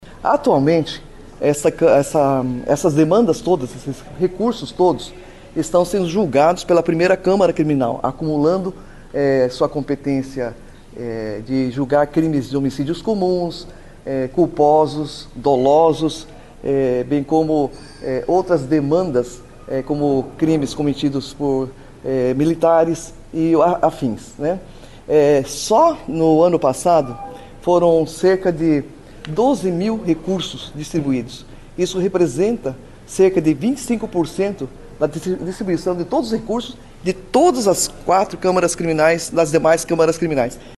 A cerimônia aconteceu na sede do Tribunal de Justiça do Paraná (TJPR), em Curitiba, e contou com a presença do governador Ratinho Junior (PSD) e de autoridades.
A presidente do TJPR destacou que a nova câmara terá como função primordial a de desafogar os casos em tramitação em outras câmaras.